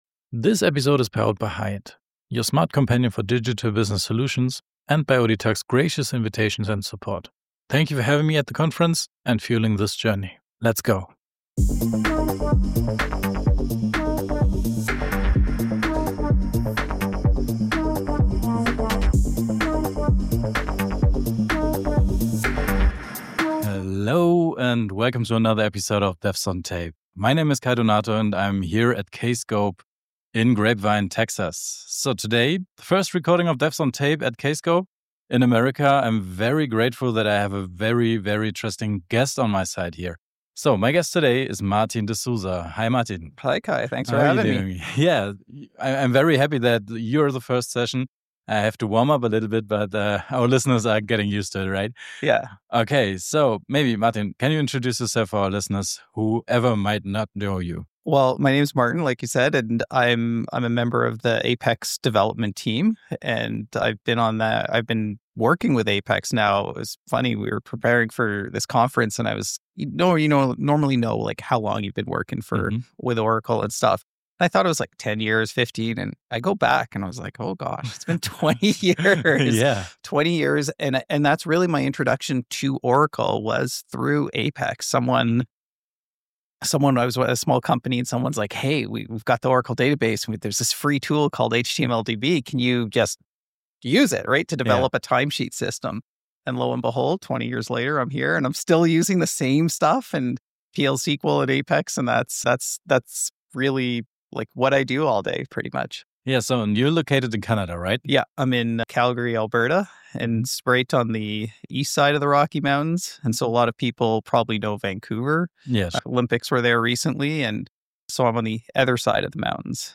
Play Rate Listened List Bookmark Get this podcast via API From The Podcast "Devs on Tape" ist ein Podcast, der sich mit allen Themen rund um die professionelle Software-Entwicklung beschäftigt. Zwei kreative Köpfe teilen Ihr Wissen und ihre Erfahrungen im Bereich Development, DevOps, Vereinsarbeit und vielem mehr.